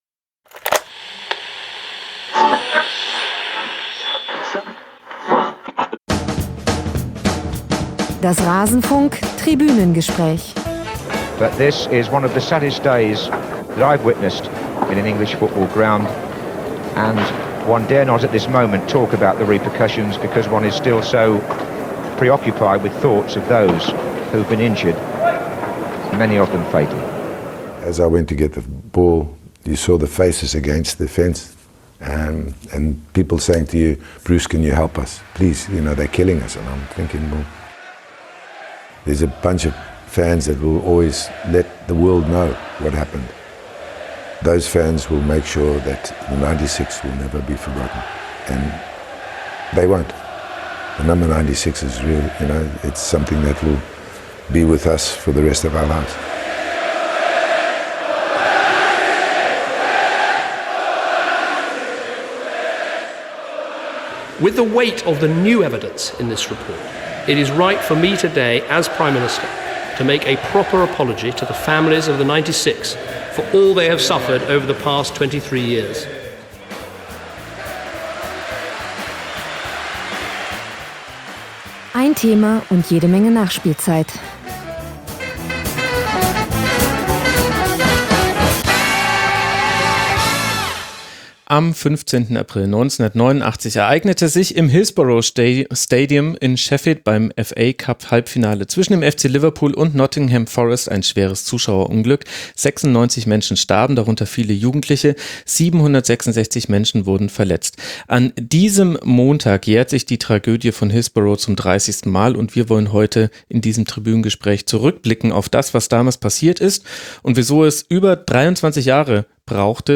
Doch es dauerte über 20 Jahre, bis die Angehörigen Gewissheit über seinen Hergang hatten. Ein Gespräch